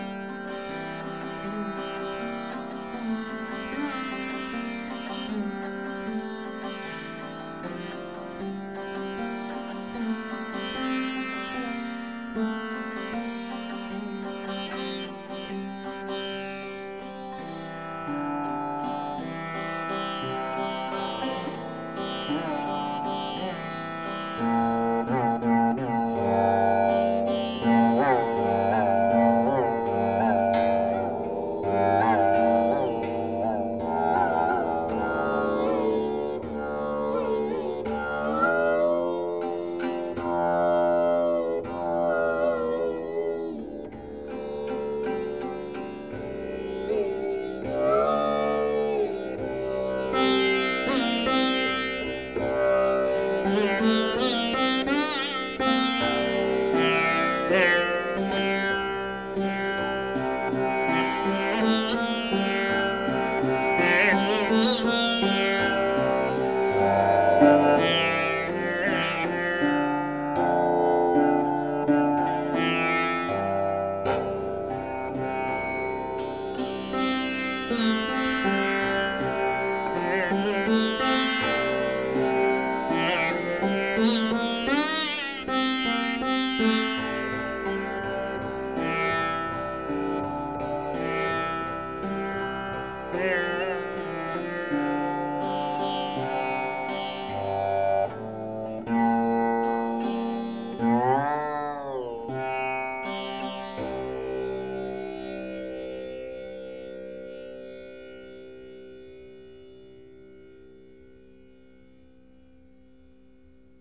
Les grandes dimensions de cet instrument permet d’obtenir une augmentation très importante de la puissance acoustique et de la durée du son.
L’instrument possède 3 jeux de cordes sympathiques intercalés entre les 3 octaves de cordes mélodiques.
Le kotar est en suspension sur une structure « en berceau » évitant ainsi les pertes acoustiques dues au support : la puissance sonore s’en trouve encore augmentée.